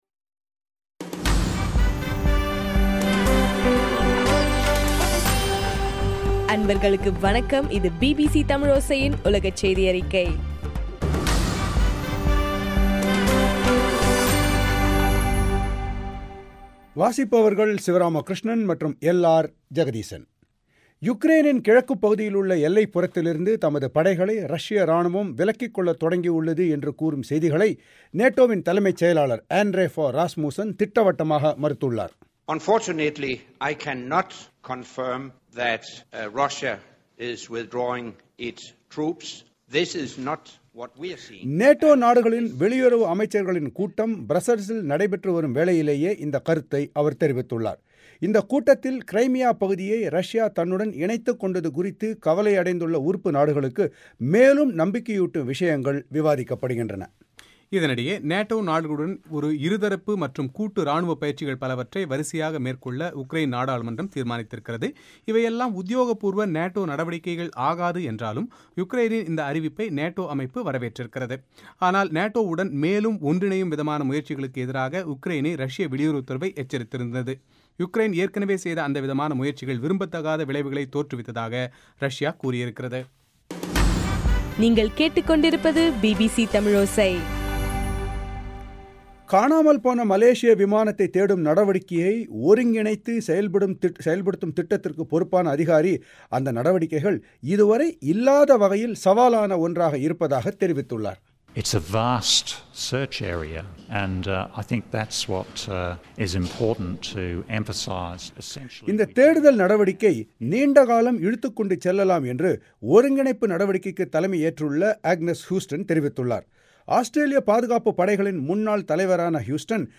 இன்றைய (ஏப்ரல் 1) பிபிசி தமிழோசை உலகச் செய்தி அறிக்கை